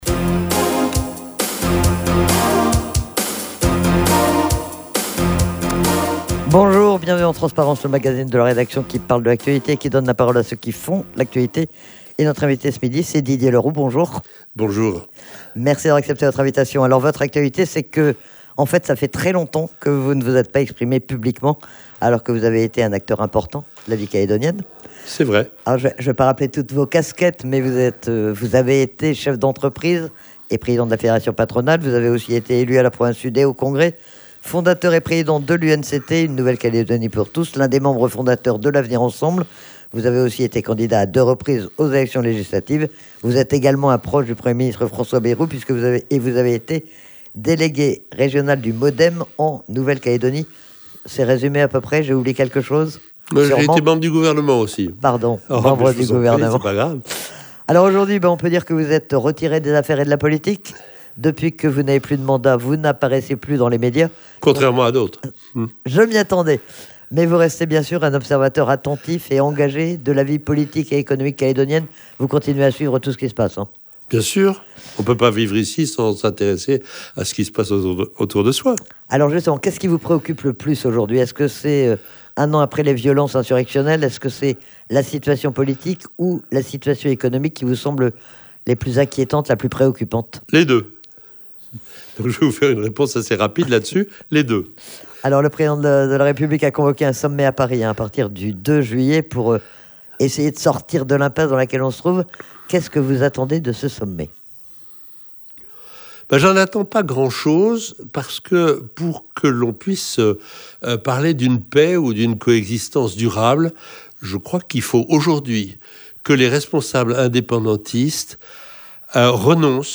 Il est interrogé sur l'analyse qu'il fait de la situation, plus d'un an après les violences de mai 2024 et à quelques jours du sommet de Paris.